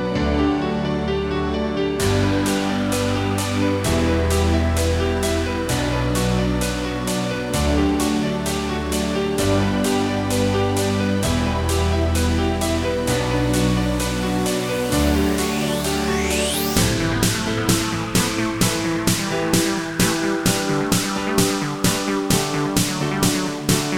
no Backing Vocals Dance 3:31 Buy £1.50